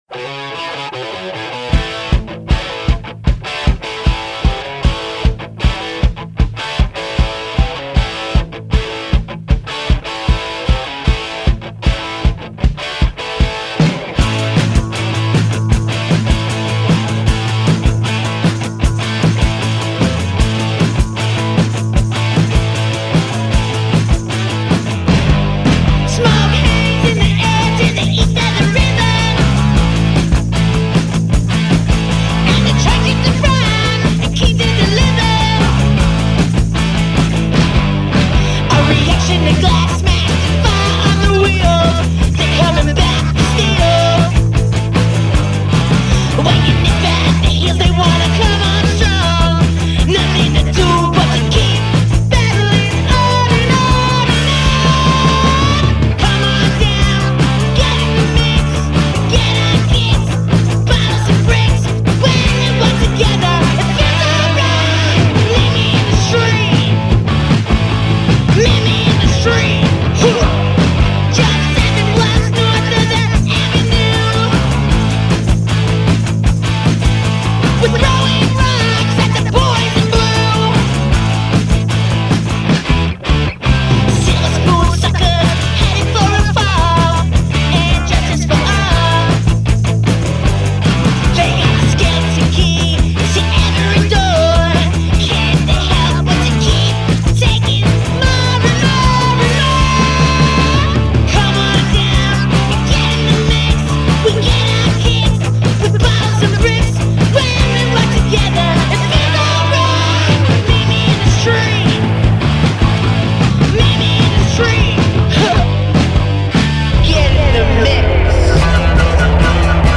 hard rockers
and the twin-guitar Camaro-blasting sounds of Thin Lizzy.